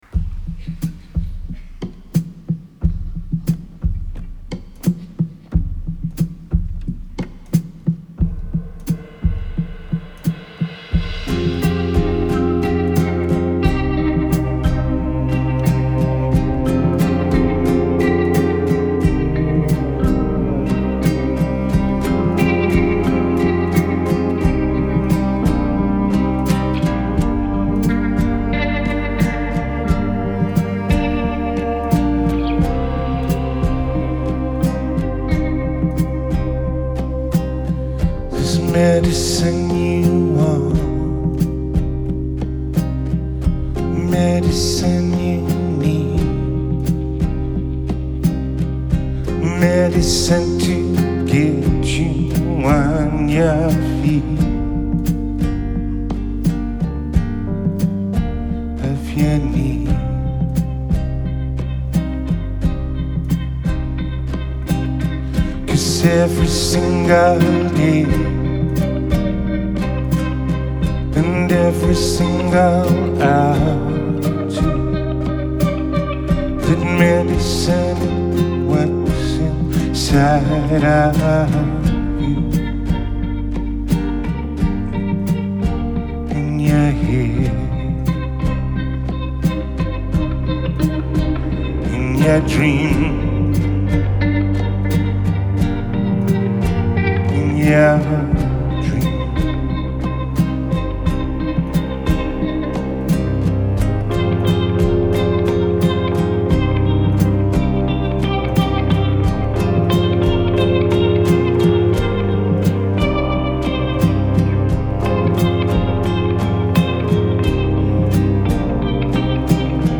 Genre: Alternative, Indie Rock, Chamber Pop